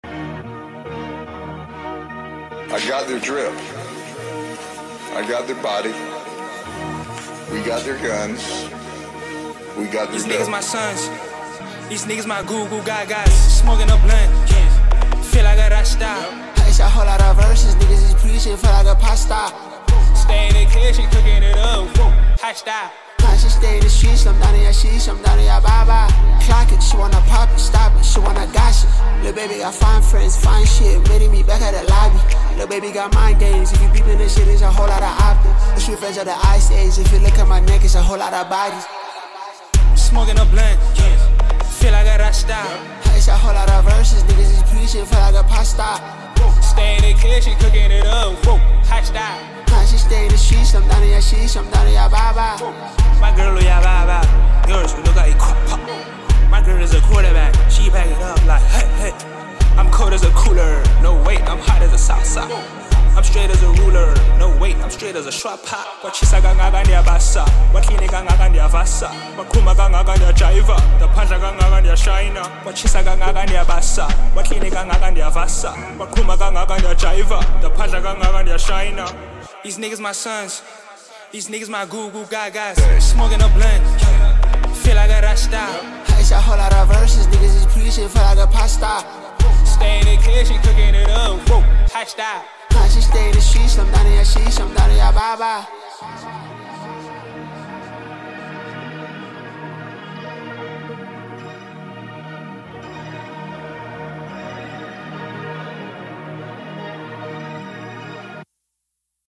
talented South African singer
heartwarming recording